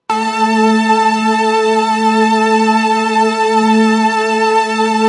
描述：我想，这就是我的梦想。轻微的效果发送，使其温暖一些。听起来不像是弦乐，这只是我想要的，但这是我最终的结果。享受44.1k, 16bit
Tag: 模拟 街舞 多样品 字符串 合成器 虚拟模拟